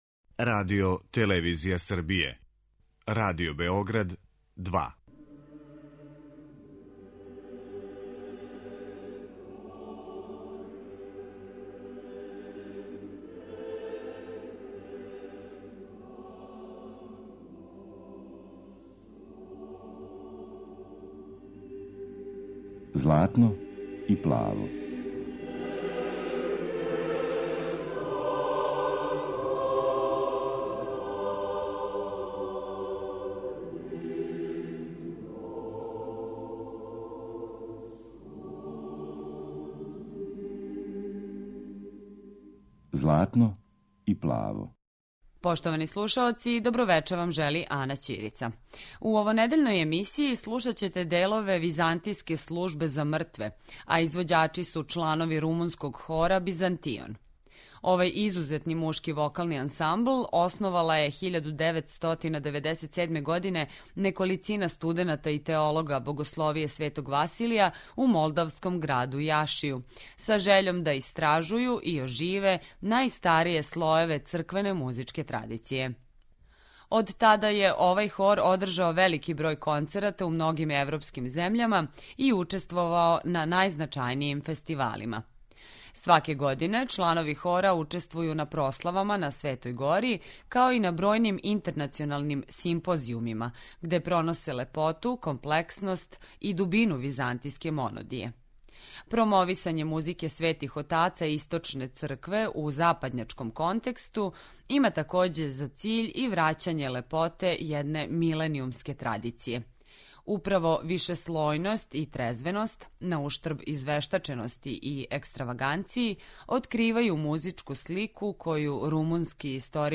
У емисији која је посвећена православној духовној музици, на Велику суботу, слушаћете Византијску службу за мртве.